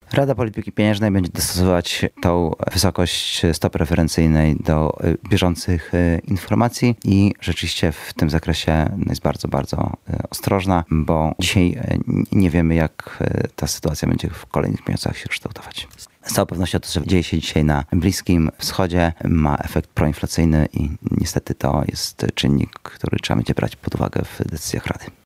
Im dłużej ten konflikt będzie trwał, tym bardziej będzie odczuwalny dla naszej inflacji – mówi Radiu Lublin członek zarządu Narodowego Banku Polskiego Artur Soboń.